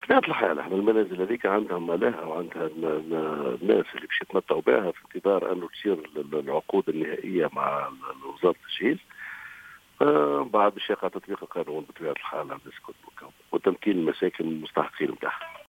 وأضاف الوالي في تصريح لـ"الجوهرة أف أم" أن هذه المساكن ستسند إلى مستحقيها بعد امضاء العقود النهائية مع وزارة التجهيز.